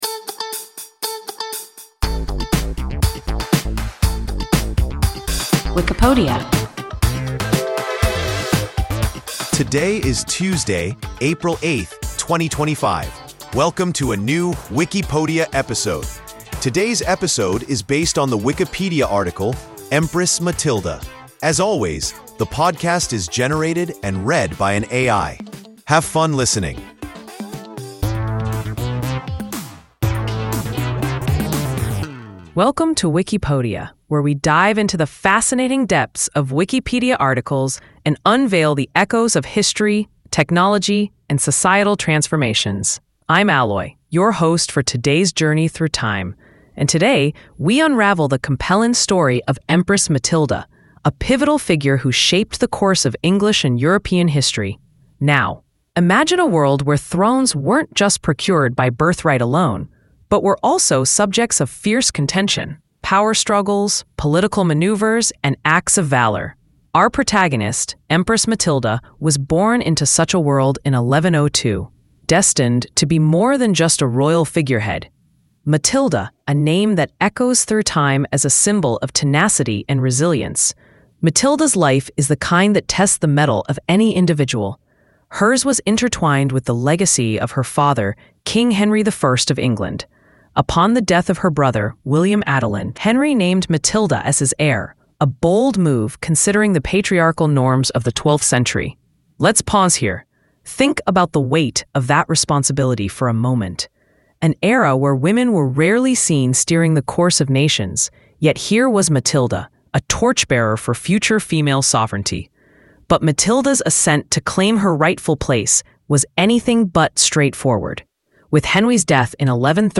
Empress Matilda – WIKIPODIA – ein KI Podcast